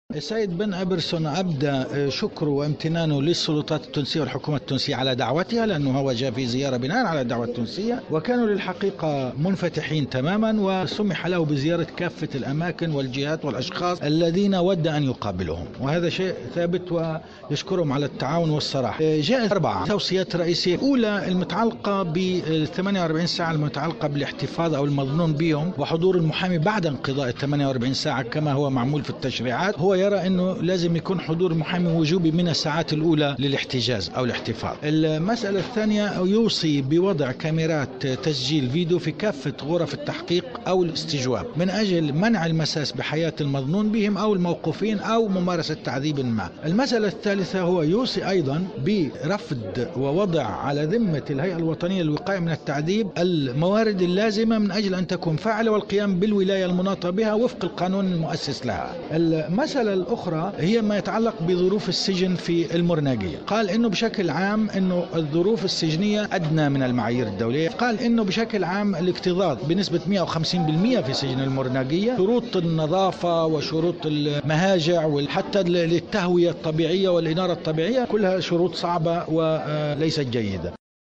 أكد "بن إيمرسون"، المقرر الخاص بحماية و تعزيز حقوق الانسان و الحريات الاساسية بالامم المتحدة ، في ندوة صحفية إنعقدت بعد ظهر اليوم الجمعة بالعاصمة، انه رغم التقدم الايجابي لسياسات تونس في مجال حقوق الانسان، إلا أن بعض الإجراءات تستحق المراجعة ومزيد التطوير، كطول مدة الإيقاف وشروطه، و استعمال الاوامر والقرارات الادارية لتقييد حرية التنقل، و فرض الاقامة الجبرية دون مراجعة قضائية، بالاضافة الى استعمال قانون مكافحة الارهاب عوضا عن تشريعات أخرى في مقاضاة الصحفييين.